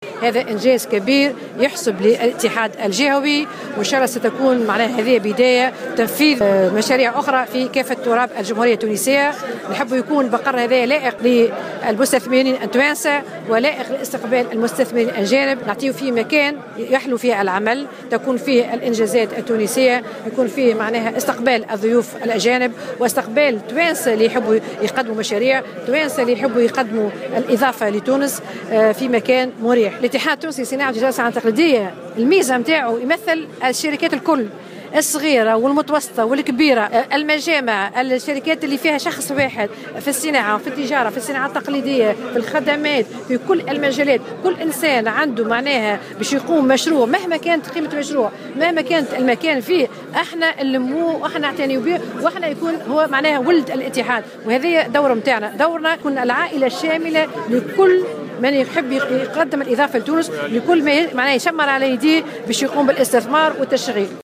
وأكدت رئيسة الإتحاد التونسي للصناعة والتجارة والصناعات التقليدية وداد بوشماوي، بمناسبة التدشين اهمية المقر جديد للاتحاد الجهوي الذي سيكون فضاء لائقا لاحتضان رجال الأعمال من تونس بالاضافة إلى رجال أعمال أجانب، بحسب تعبيرها.